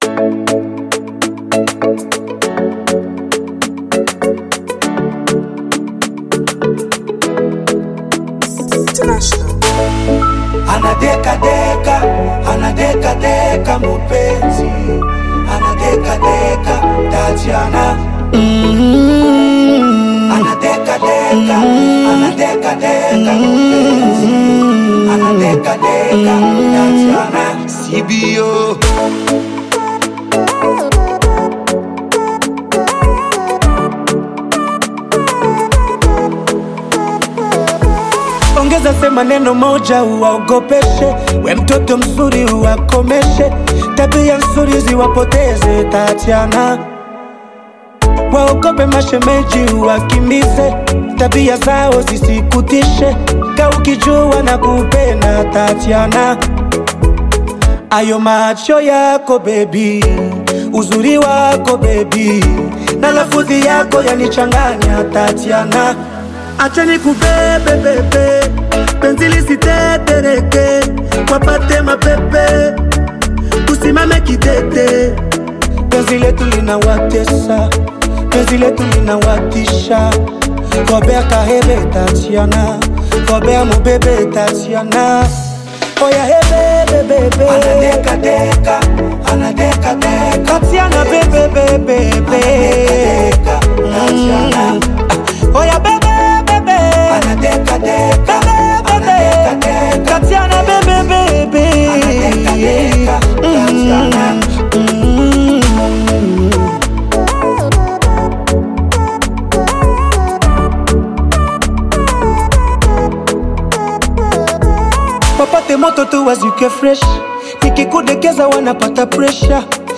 Tanzanian Bongo Flava rumba
African Music